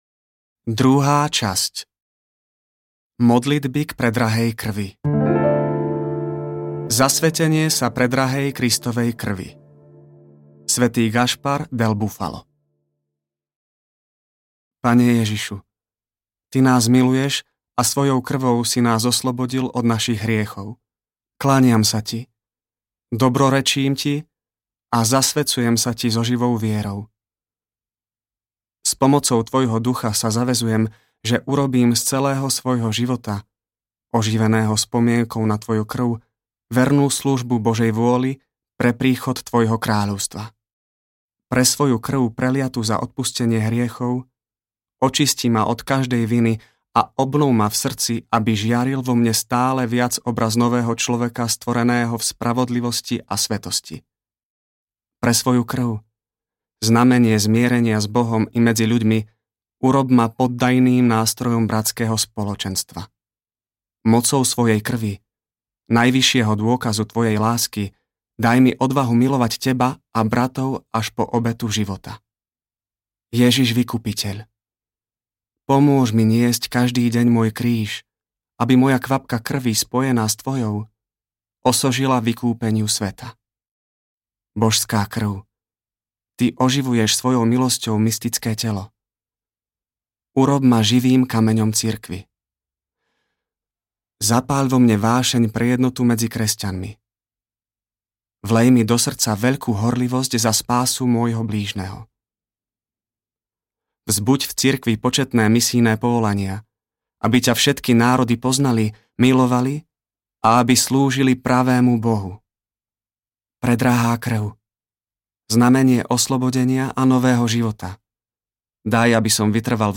Modlitby ku Kristovej krvi audiokniha
Ukázka z knihy